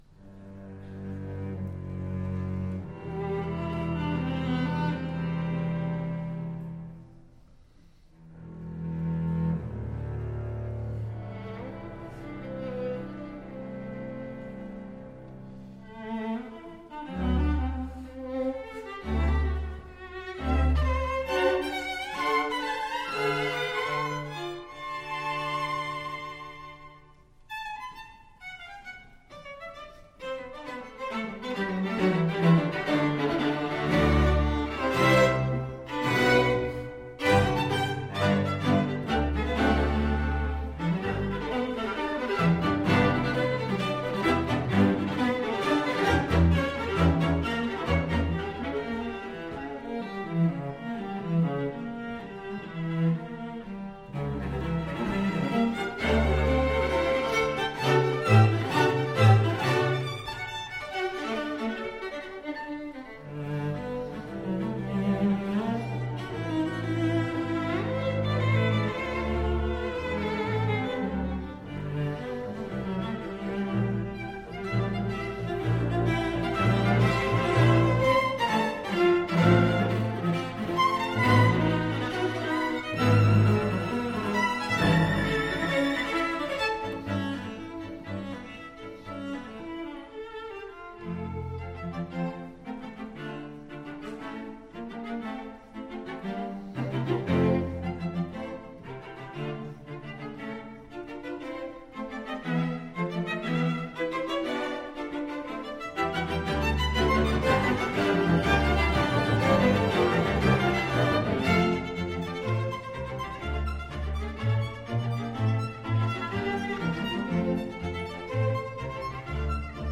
String Quintet No.2, Op.77
Style: Classical
Audio: Boston - Isabella Stewart Gardner Museum
Audio: Chamber Music Society of Lincoln Center